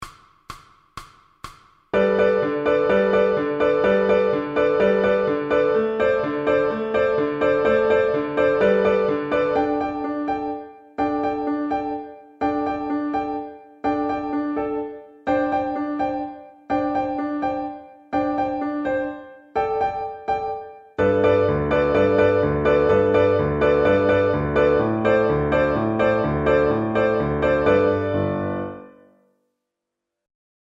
recorded piano accompaniments
Exam Speed